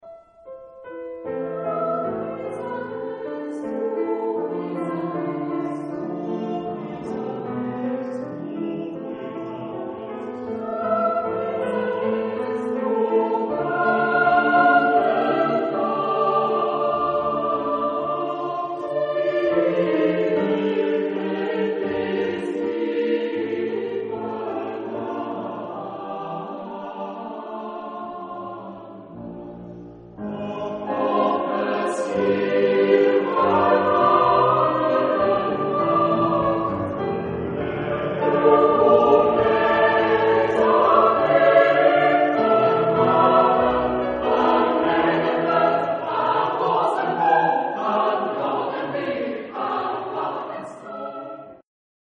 Epoque: 19th century
Genre-Style-Form: Canticle ; Sacred ; Romantic
Type of Choir: SATB  (4 mixed voices )
Instruments: Piano (1)
Tonality: A minor